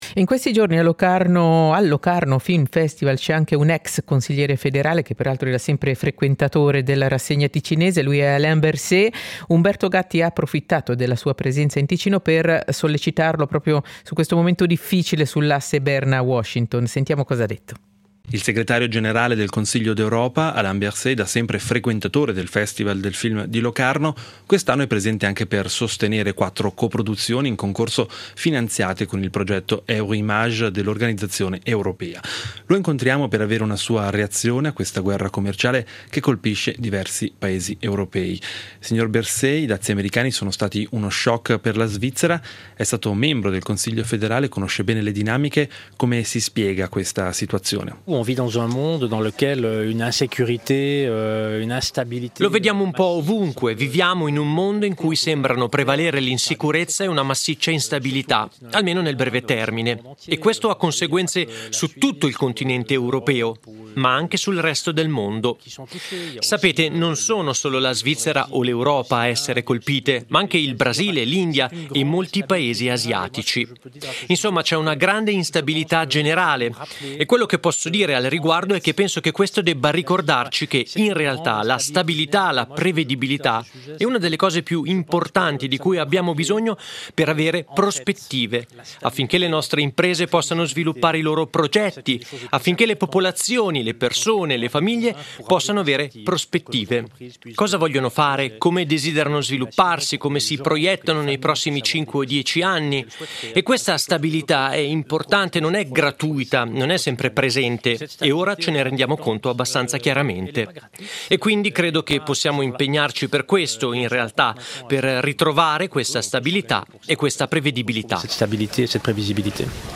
SEIDISERA del 08.08.2025: Dazi americani, intervista a Berset